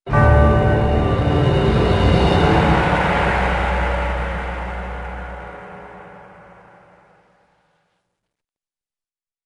game_entry.wav
乐器类/重大事件短旋律－宏大/game_entry.wav